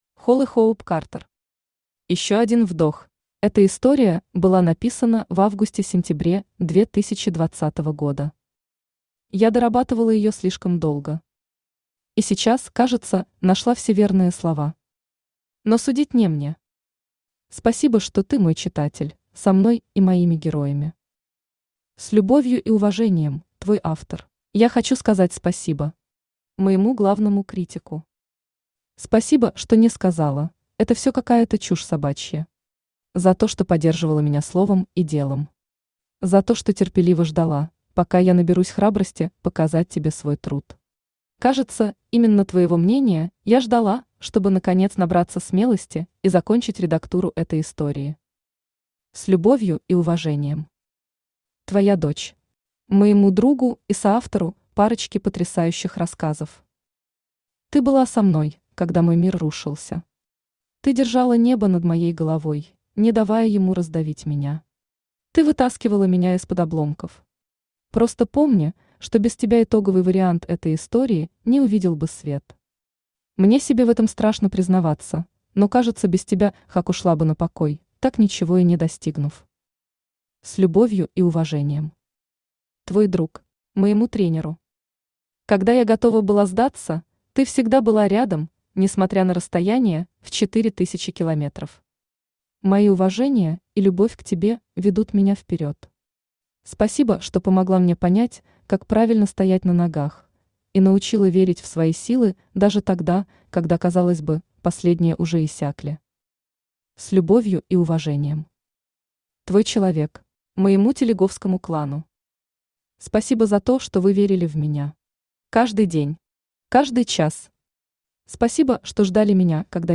Аудиокнига Ещё один вдох | Библиотека аудиокниг
Aудиокнига Ещё один вдох Автор Holly Hope Karter Читает аудиокнигу Авточтец ЛитРес.